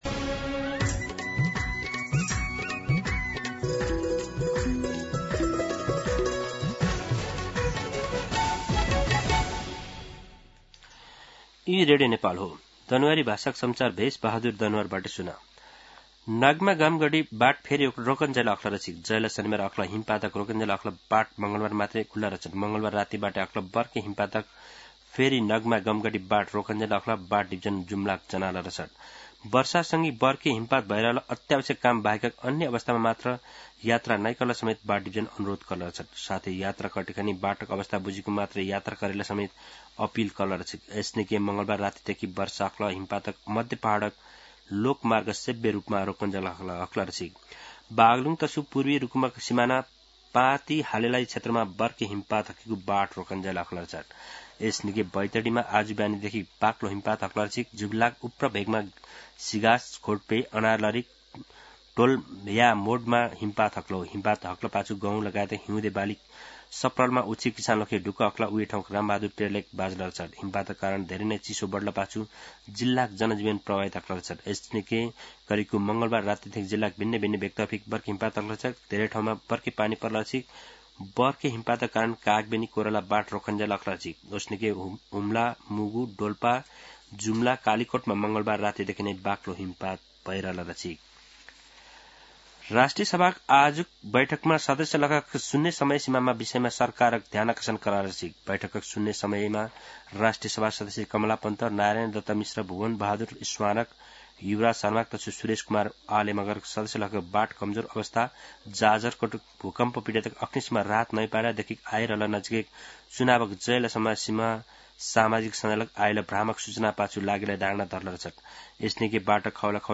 दनुवार भाषामा समाचार : १४ माघ , २०८२
Danuwar-News-10-14.mp3